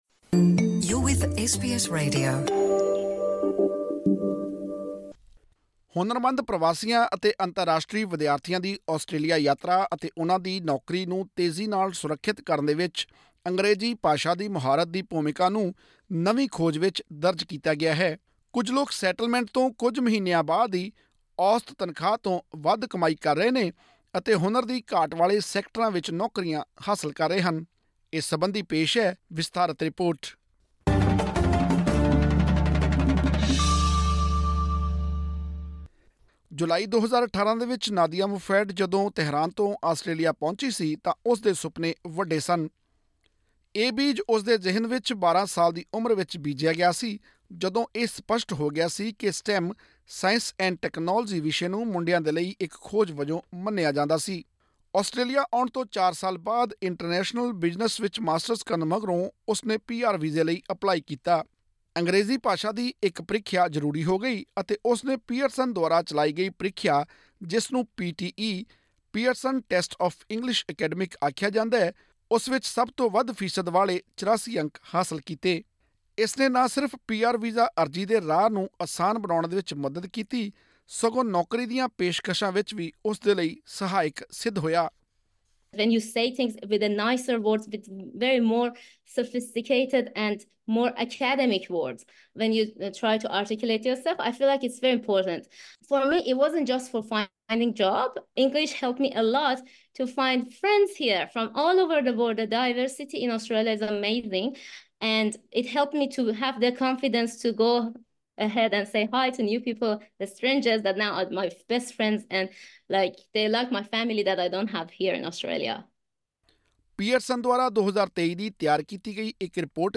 ਆਸਟ੍ਰੇਲੀਆ ਦੀ ਆਰਥਿਕ ਵਿਕਾਸ ਕਮੇਟੀ ਦੀ 13 ਮਾਰਚ ਨੂੰ ਜਾਰੀ ਹੋਈ ਰਿਪੋਰਟ ਦਰਸਾਉਂਦੀ ਹੈ ਕਿ ਅੰਗਰੇਜ਼ੀ ਭਾਸ਼ਾ ਦੀ ਮੁਹਾਰਤ ਘੱਟ ਹੋਣ ਕਾਰਨ ਪ੍ਰਵਾਸੀਆਂ ਨੂੰ ਤਨਖਾਹਾਂ ਵੀ ਘੱਟ ਮਿਲੀਆਂ ਹਨ। ਖੋਜ ਦੌਰਾਨ ਸਾਹਮਣੇ ਆਇਆ ਕਿ ਆਸਟ੍ਰੇਲੀਆ ਵਿੱਚ ਛੇ ਸਾਲਾਂ ਤੱਕ ਰਹਿਣ ਵਾਲੇ ਪ੍ਰਵਾਸੀਆਂ ਨੇ ਇੱਥੇ ਜੰਮੇ ਕਾਮਿਆਂ ਨਾਲੋਂ 10 ਫੀਸਦ ਘੱਟ ਕਮਾਈ ਕੀਤੀ ਹੈ। ਹੋਰ ਵੇਰਵੇ ਲਈ ਸੁਣੋ ਇਹ ਆਡੀਓ ਰਿਪੋਰਟ।